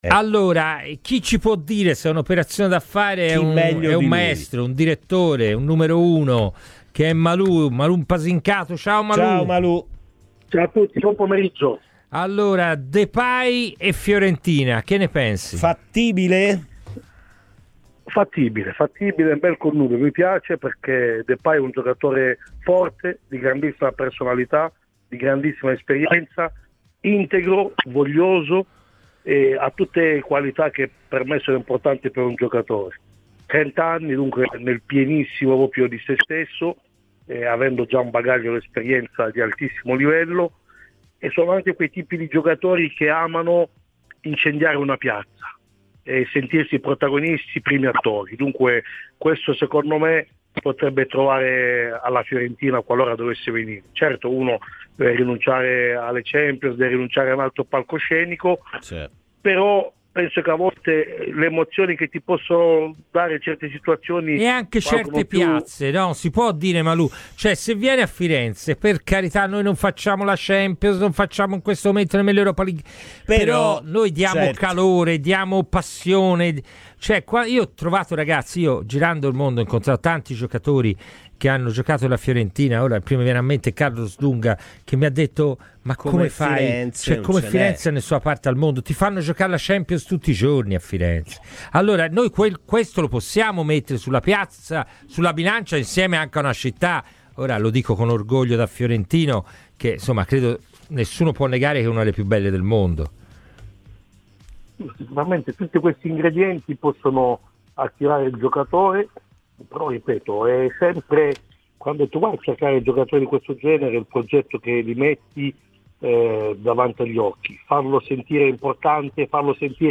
è intervenuto ai microfoni di Radio FirenzeViola durante la trasmissione 'Palla al centro'